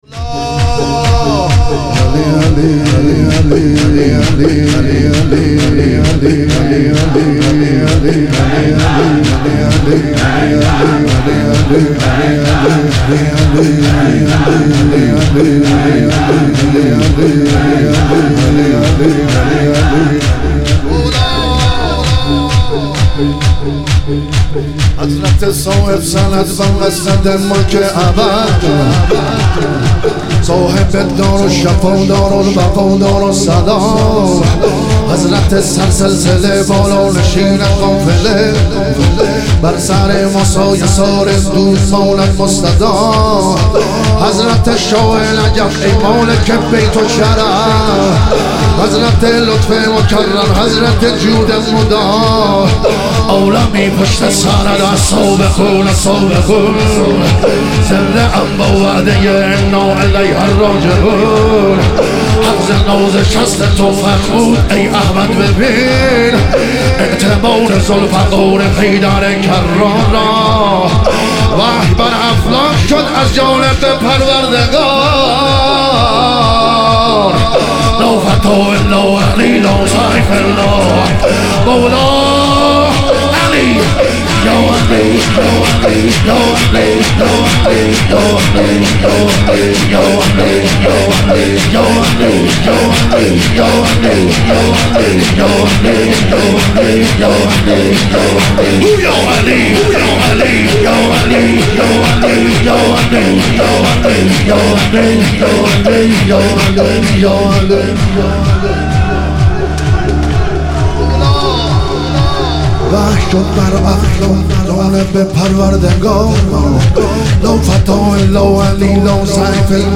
شور
شب تخریب بقیع